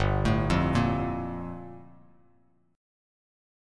Gm9 Chord
Listen to Gm9 strummed